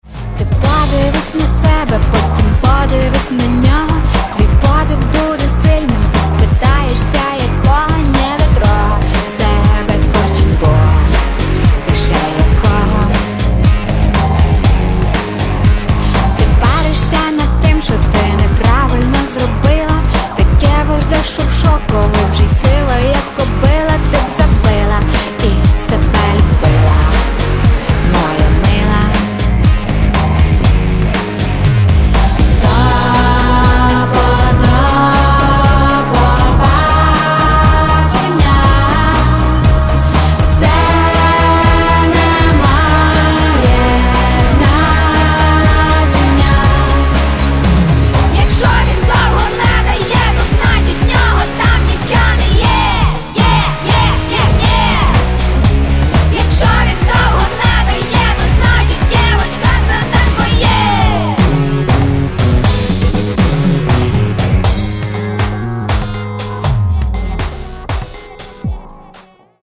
Каталог -> Рок и альтернатива -> Электронная альтернатива